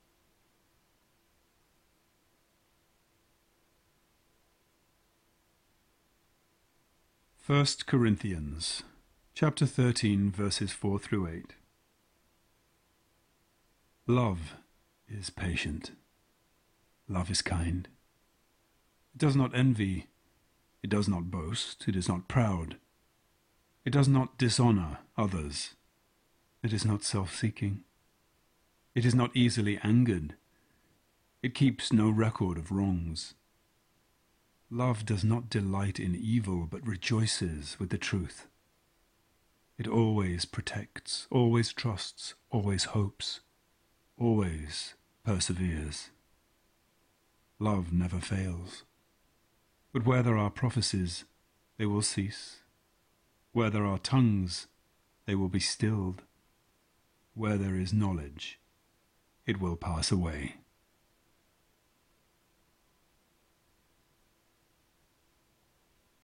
He has a little gift for us at Valentine’s. He’s recited 15 classic love poems, and they’re free.